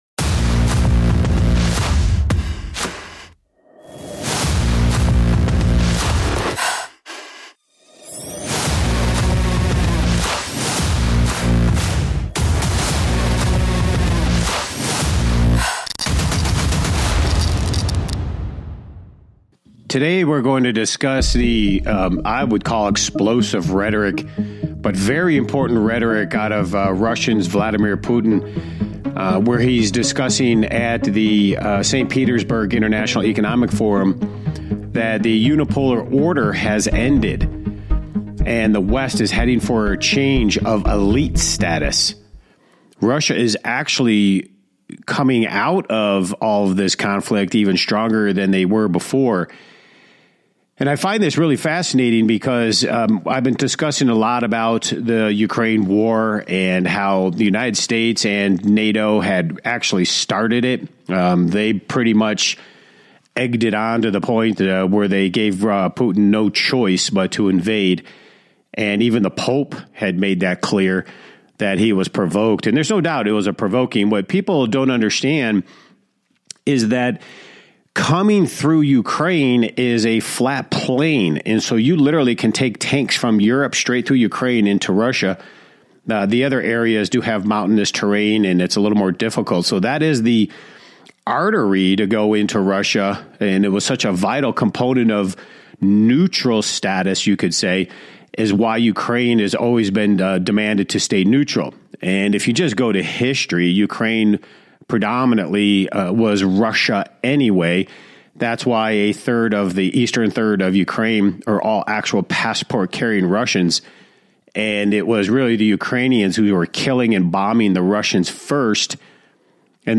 Talk Show Episode, Audio Podcast, Rigged Against You and Putin Vows That The West Will Be Destroyed on , show guests , about Putin Vows That The West Will Be Destroyed, categorized as Business,Investing and Finance,History,News,Politics & Government,Society and Culture,Technology